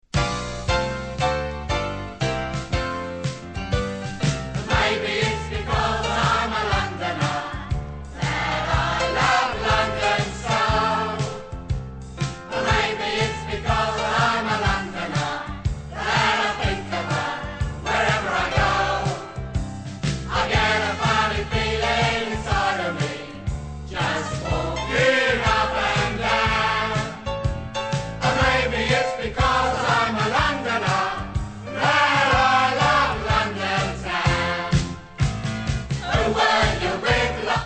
party songs
round the piano at 'The Queen Vic.'